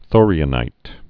(thôrē-ə-nīt)